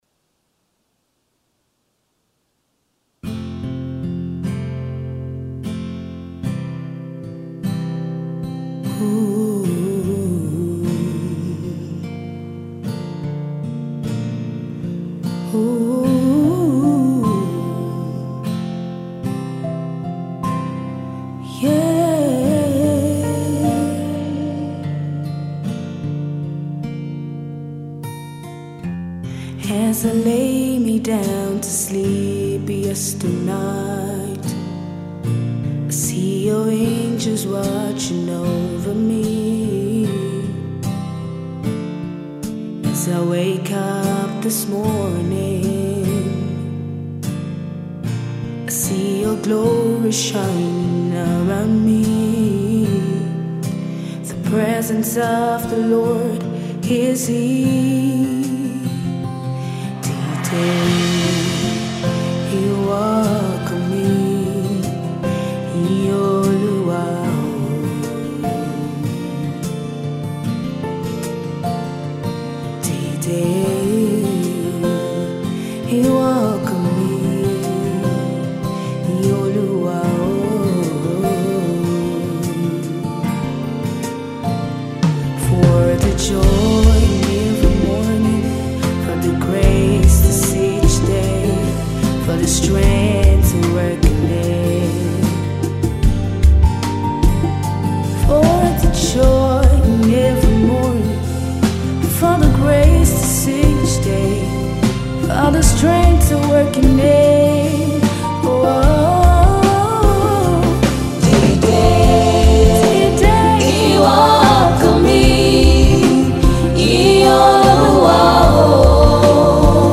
vocalist with a heart of worship unto God
inspiring song of Praise